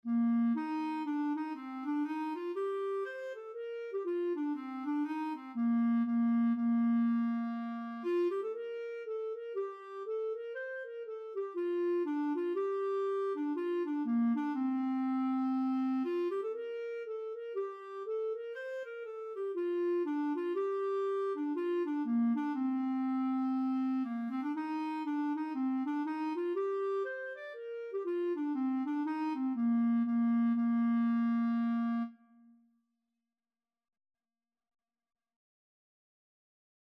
Bb major (Sounding Pitch) C major (Clarinet in Bb) (View more Bb major Music for Clarinet )
4/4 (View more 4/4 Music)
Bb4-D6
Clarinet  (View more Intermediate Clarinet Music)
Traditional (View more Traditional Clarinet Music)
Irish